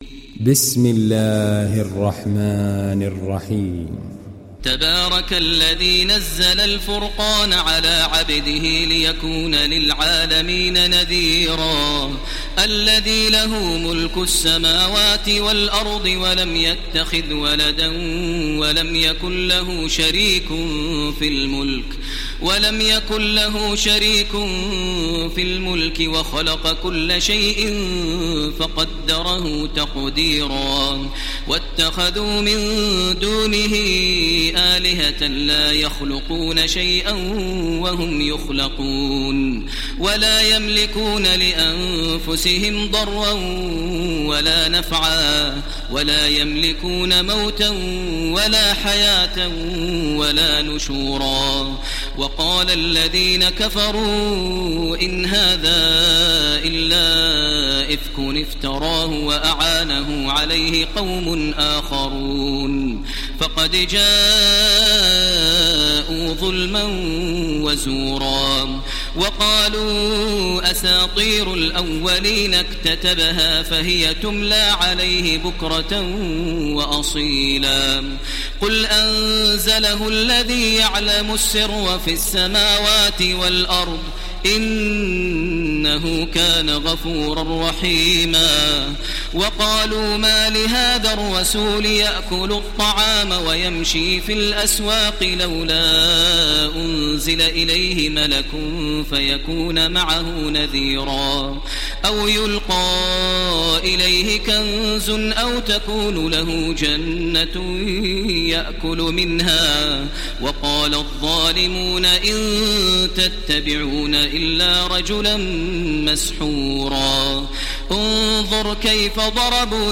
İndir Furkan Suresi Taraweeh Makkah 1430